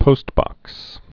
(pōstbŏks)